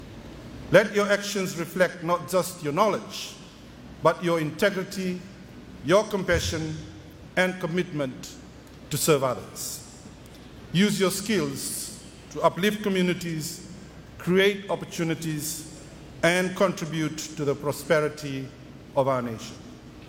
This was stressed by the Minister for Lands and Mineral Resources, Filimoni Vosarogo during his address at the Fiji National University’s College of Agriculture, Fisheries, Forestry and College of Business,